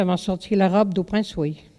Localisation Bois-de-Céné
collecte de locutions vernaculaires
Catégorie Locution